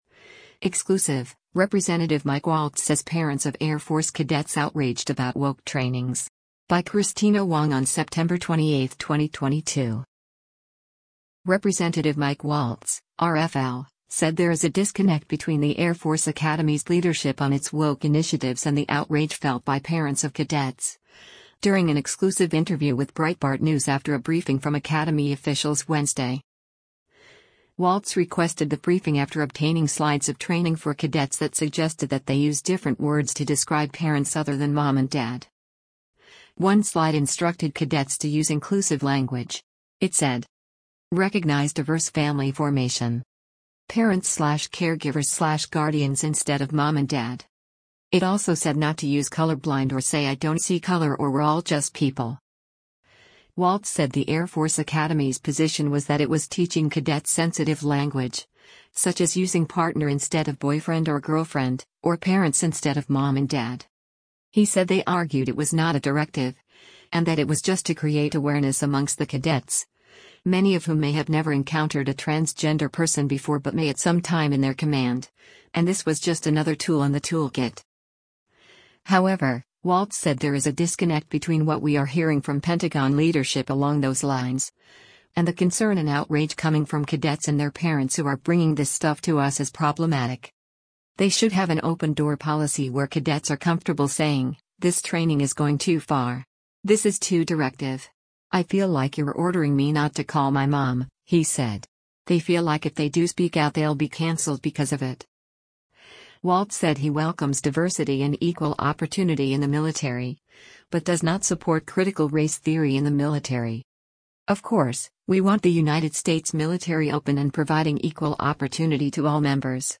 Exclusive: Rep. Mike Waltz Says Parents of Air Force Cadets Outraged About Woke Trainings
Rep. Mike Waltz (R-FL) said there is a “disconnect” between the Air Force Academy’s leadership on its woke initiatives and the outrage felt by parents of cadets, during an exclusive interview with Breitbart News after a briefing from academy officials Wednesday.